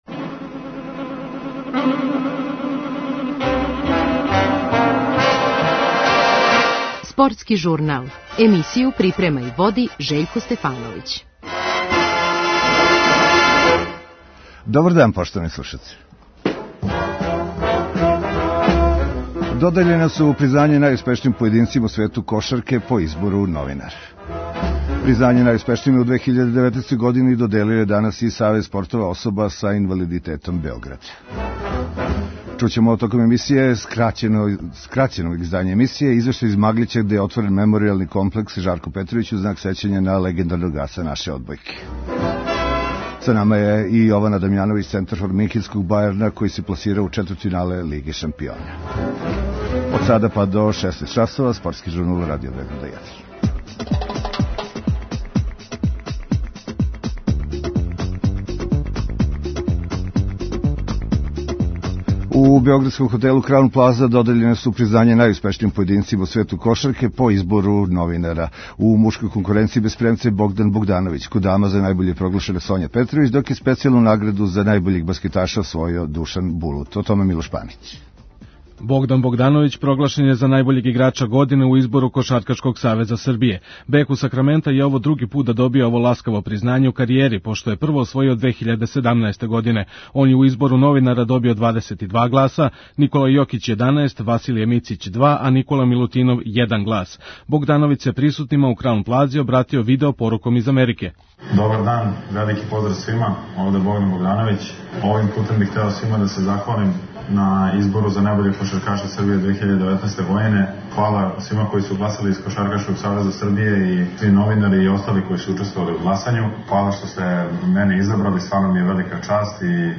Признања најуспешнијима у 2019. години доделио је данас и Савез спортова особа са инвалидитетом Београда. У данашњем, скраћеном издању `Спортског журнала`, чућемо и извештај из Маглића, где је отворен меморијални комплекс `Жарко Петровић`, у знак сећања на легендарног аса наше одбојке.